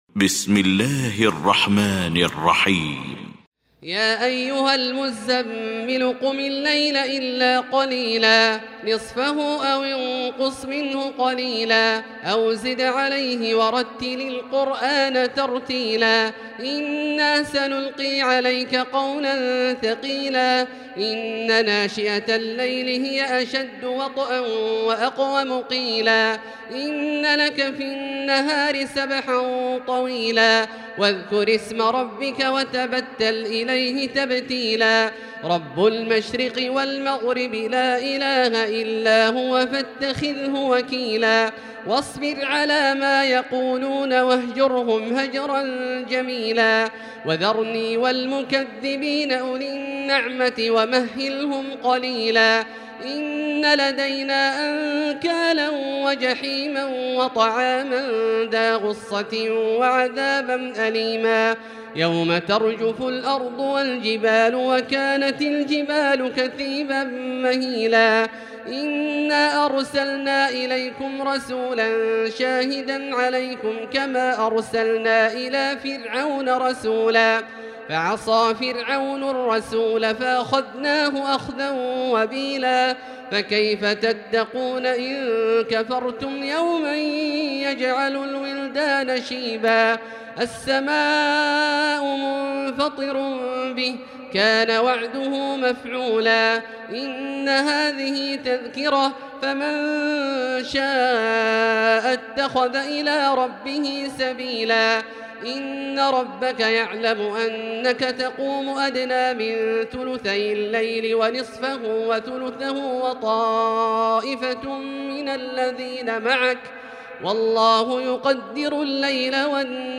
المكان: المسجد الحرام الشيخ: فضيلة الشيخ عبدالله الجهني فضيلة الشيخ عبدالله الجهني المزمل